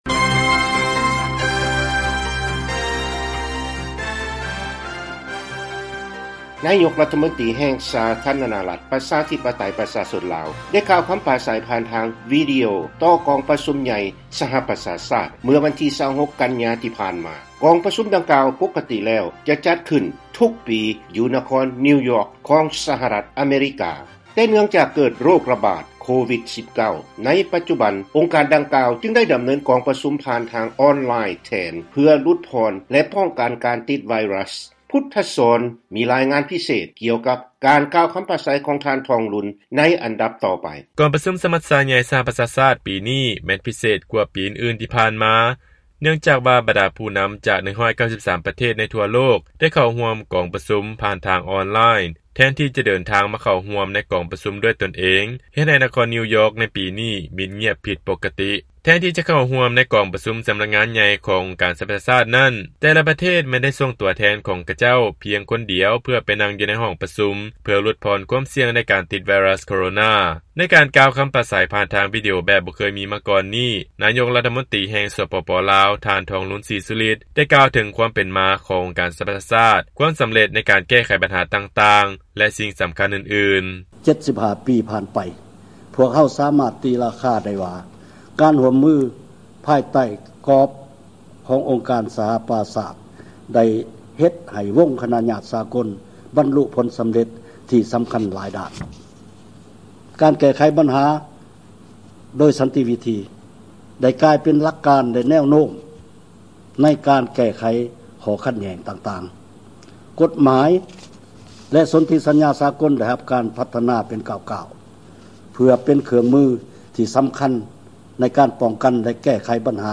ລາຍງານພິເສດ ການກ່າວຄຳປາໄສ ທີ່ກອງປະຊຸມ ສະມັດຊາໃຫຍ່ ສະຫະປະຊາຊາດ ຂອງນາຍົກລັດຖະມົນຕີ ລາວ